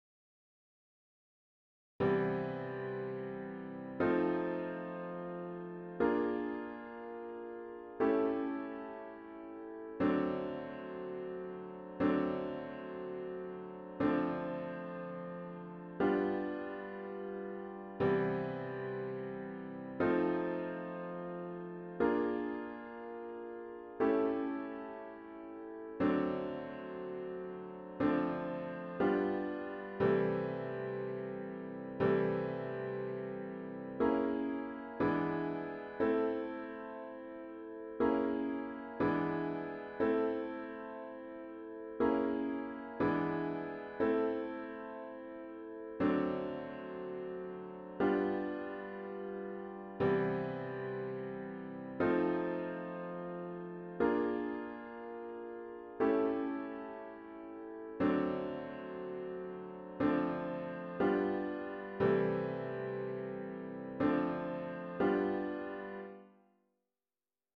An audio of the chords only version is